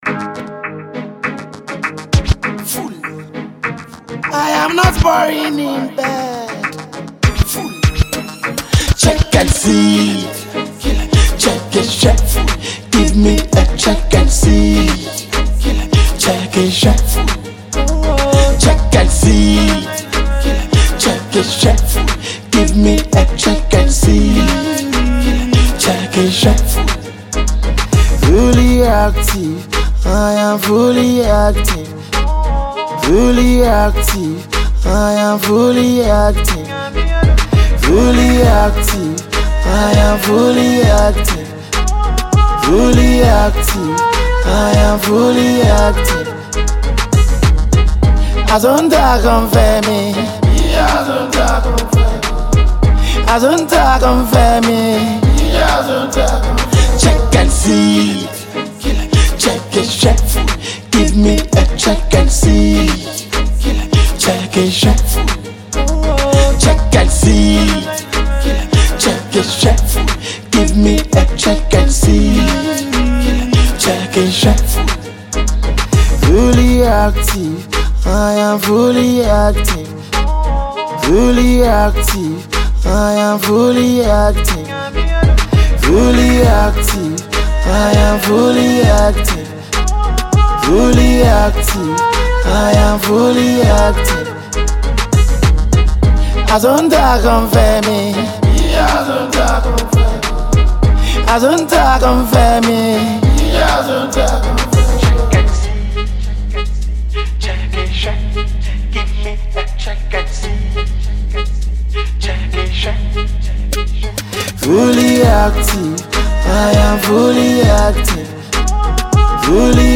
Ghana Afrobeat MP3